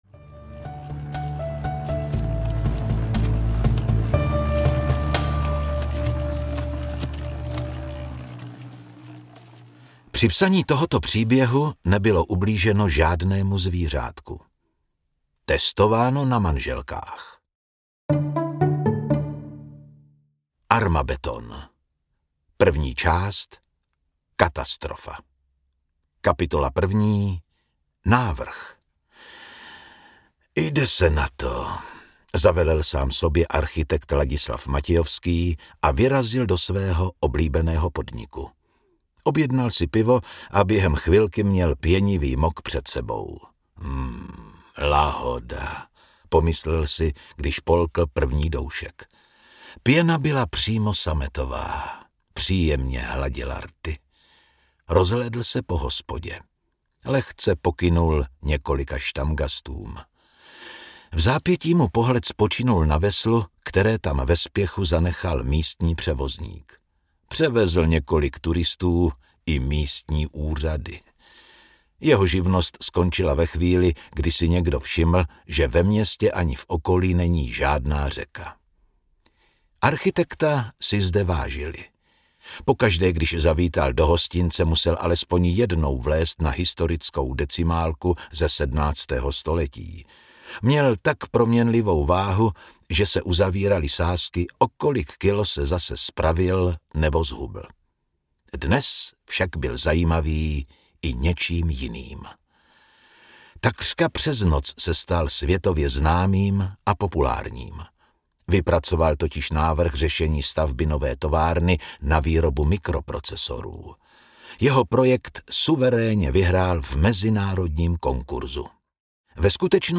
Armabetton audiokniha
Ukázka z knihy
• InterpretZdeněk Junák